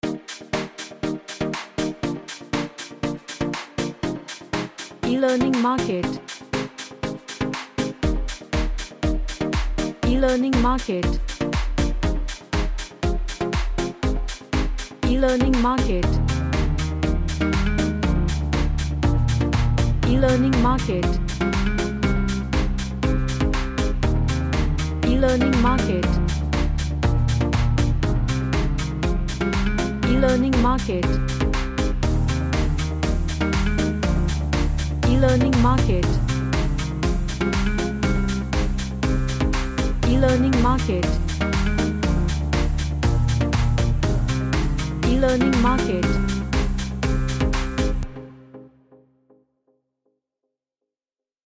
A Summer track with pluck chords.
Happy